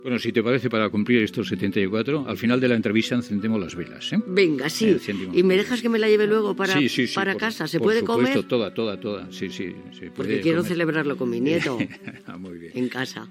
Fragment curt d'una entrevista a l'actriu Concha Velasco, quan complia 74 anys.